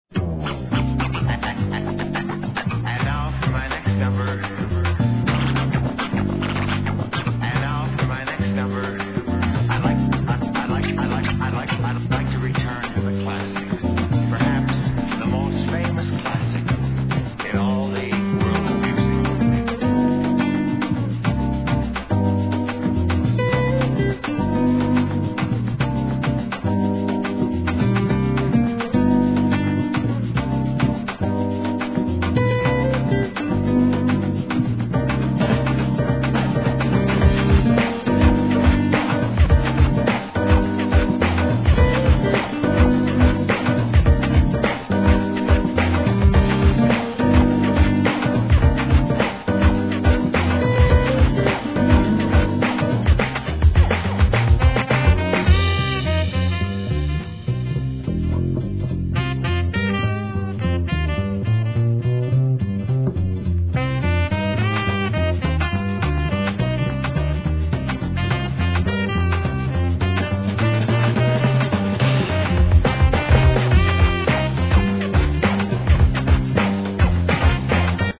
JAPANESE HIP HOP/R&B
国産ジャジー・ヒップホップ×ハウス最前線によるクロスオーヴァーなコラボレーション！！